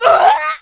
death3c.wav